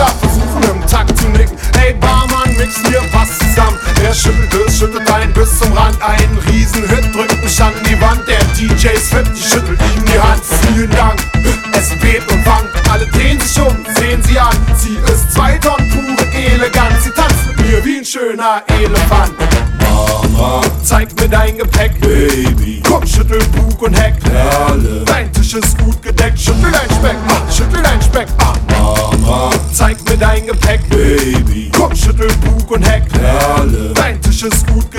Pop, Dance, Hip-Hop, Rap, Alternative Rap, Reggae